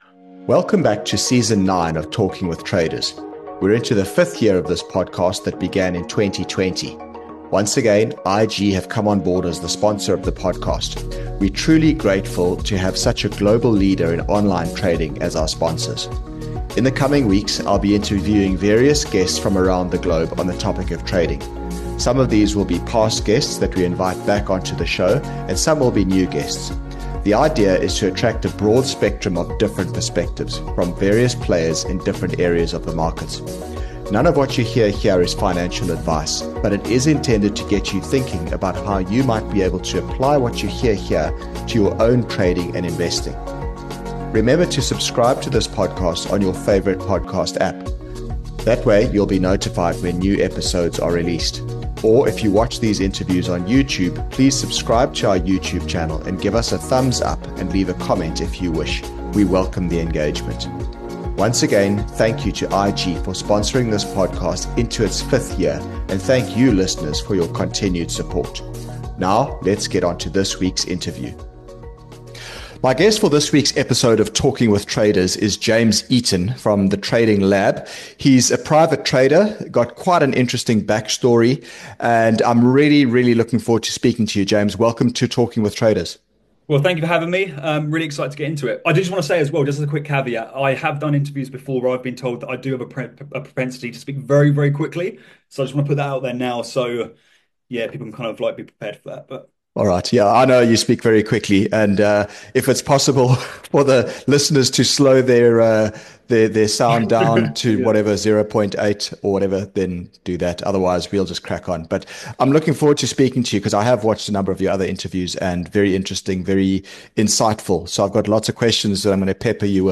It made for a really refreshing, insightful interview.